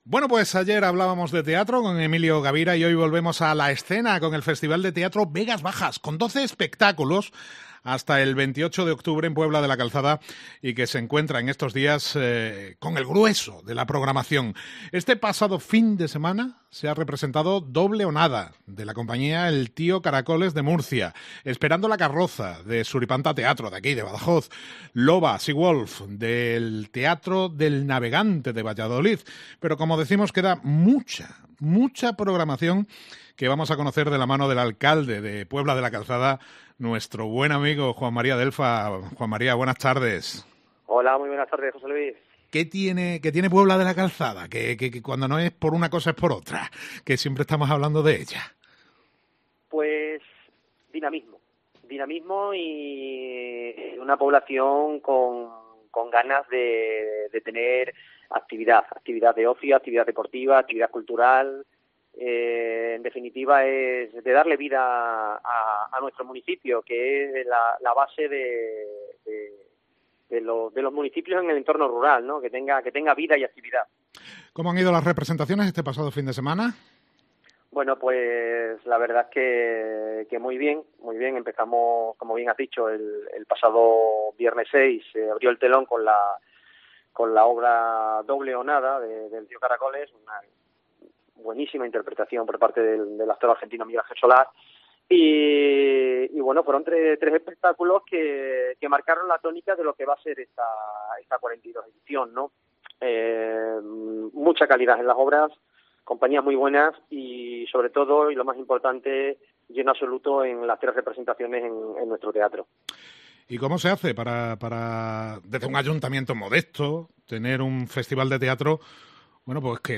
Juan María Delfa, alcalde de Puebla de la Calzada, nos habla del Festival de Teatro Vegas Bajas